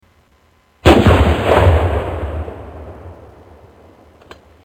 Single gunshot